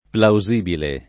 [ plau @& bile ]